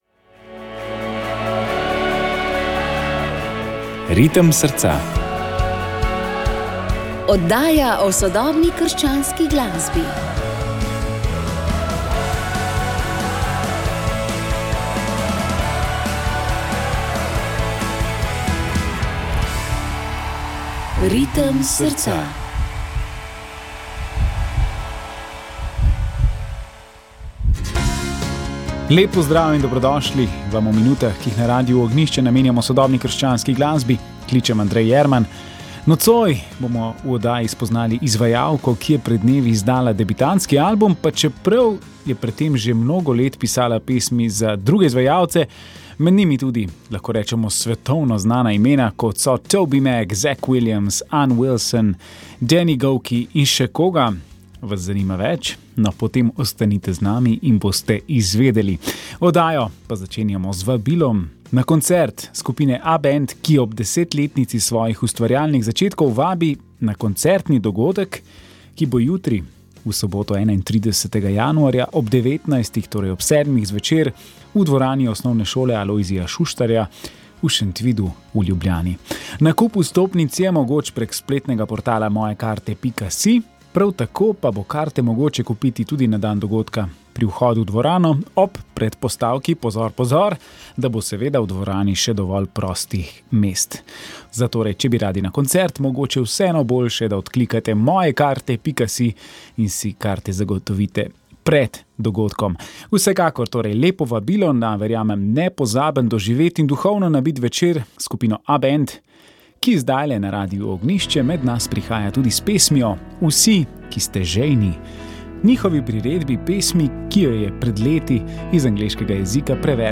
Izvedeli boste, da vera raste kot seme v zemlji in se podučili kaj potrebuje za rast. Pred četrtim Svetovnim dnem starih staršev pa smo posebej zanje zavrteli pesem ali dve.